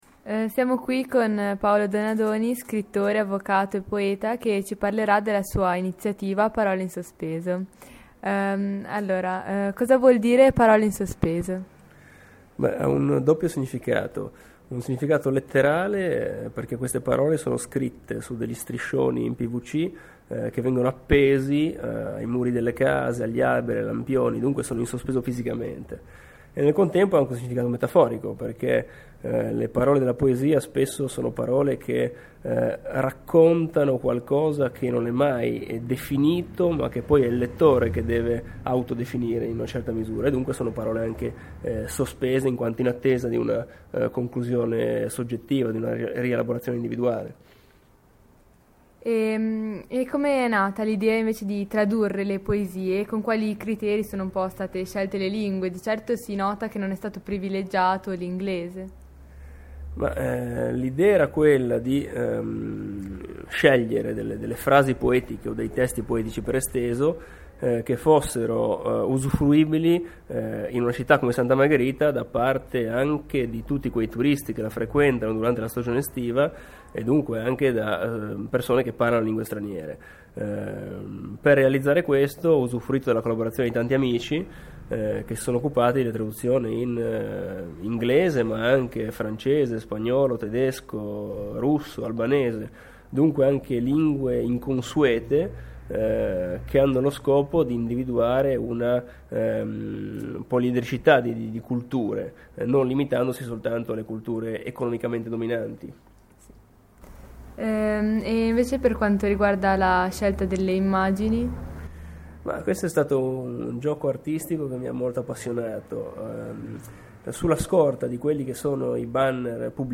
Radioweb C.A.G. di Rapallo